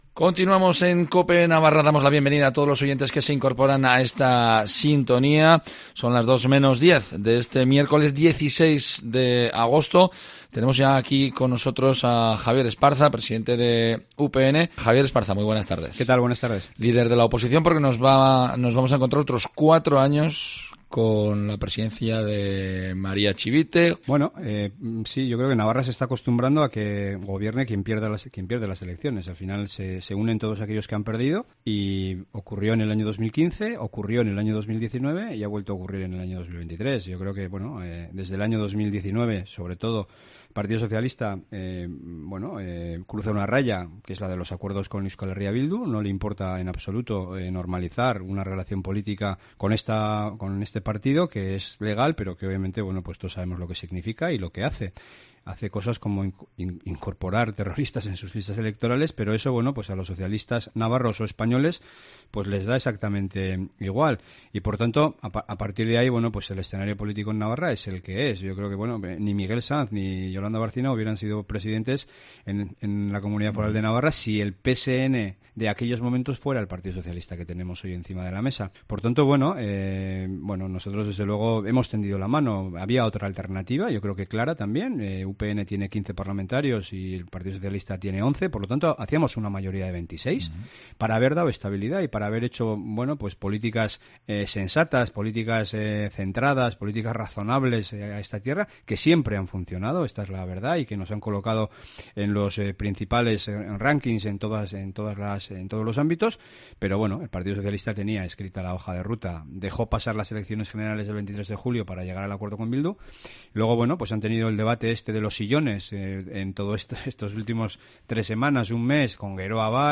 Javier Esparza, presidente de UPN, cuenta en nuestros micrófonos cómo ve esta nueva legislatura.